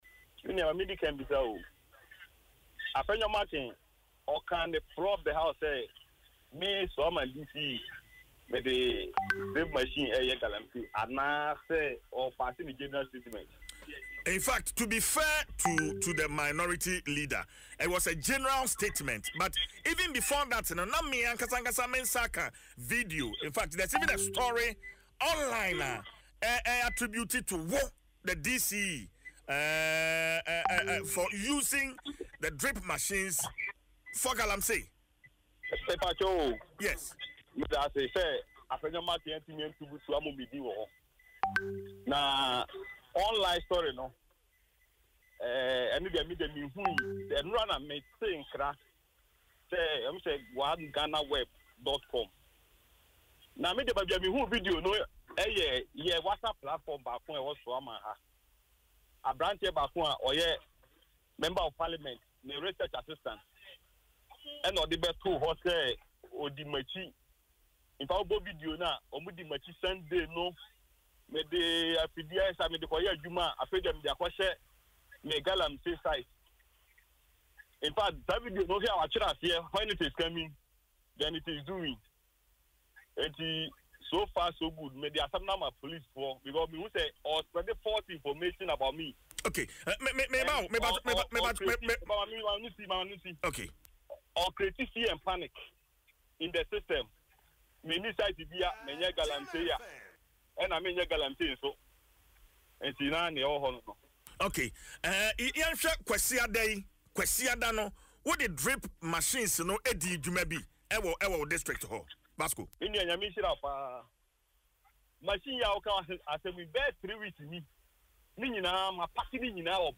Speaking in an interview on Adom FM’s Dwaso Nsem, Mr. Vasco dismissed the video as misleading and threatened legal action against the individuals behind the video and media outlets that published the story without verification.